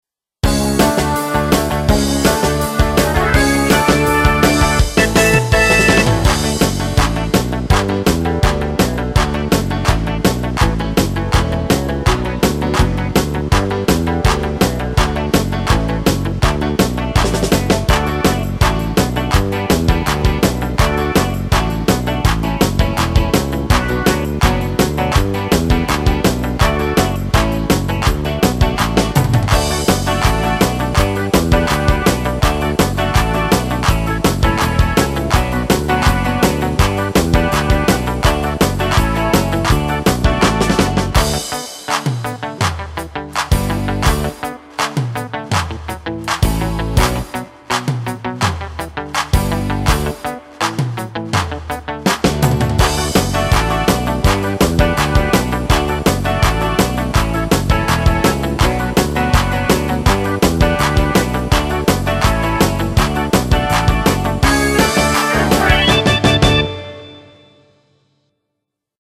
animal karaoke